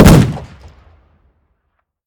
shotgun-shot-7.ogg